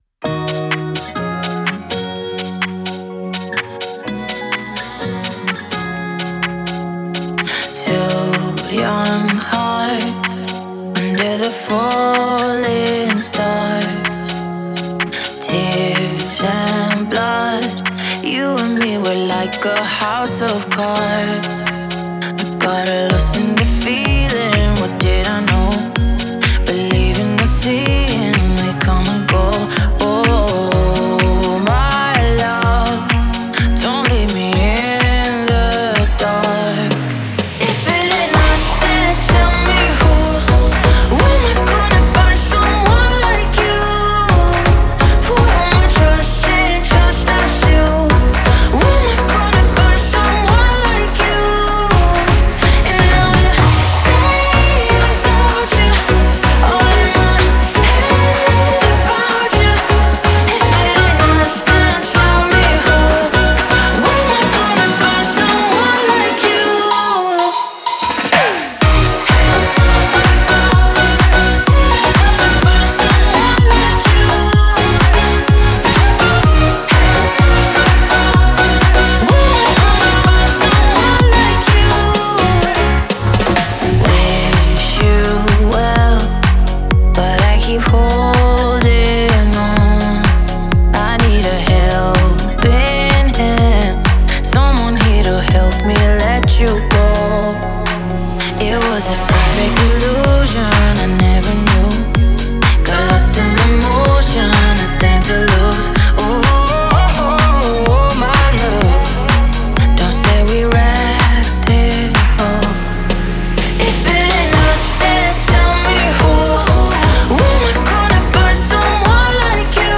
Звучание трека выделяется яркими синтезаторами